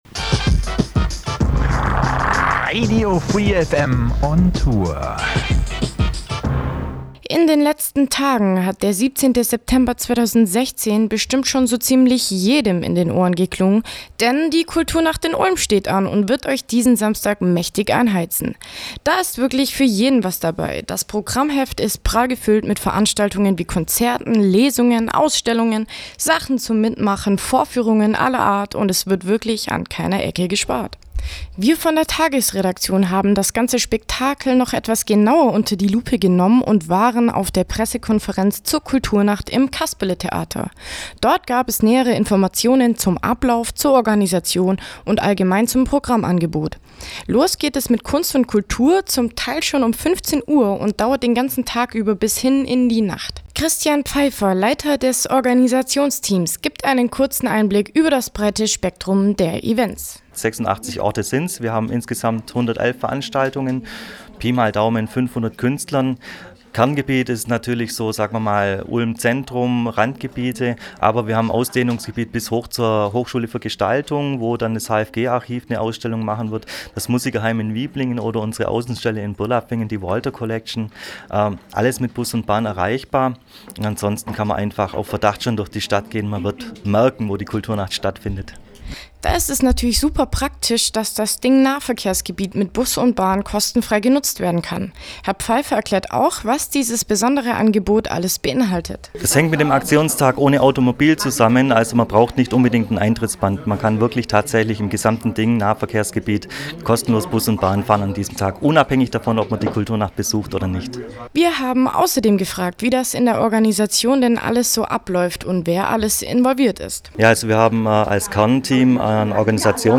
auf der Pressekonferenz zur Kulturnacht